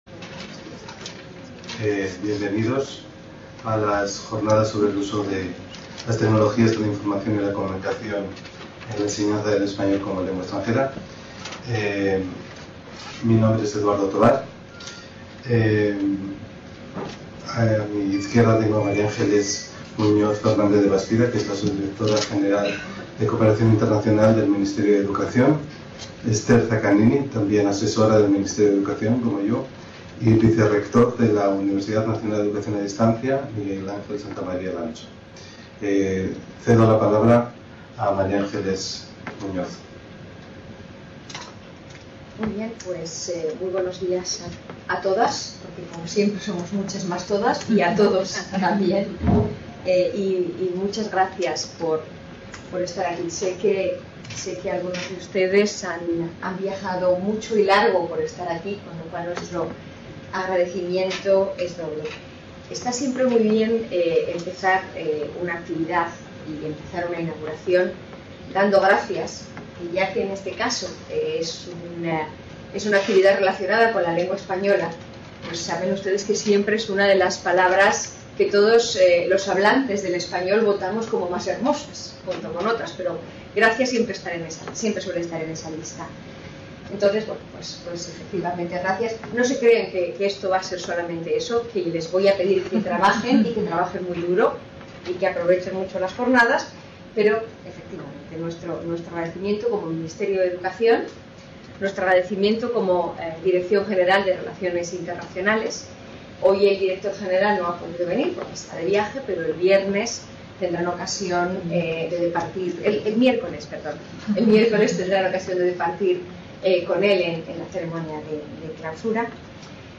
Inauguración - Dirección General de Relaciones Internacionales del Ministerio de Educación
| Red: UNED | Centro: UNED | Asig: Reunion, debate, coloquio... | Tit: CONFERENCIAS | Autor:varios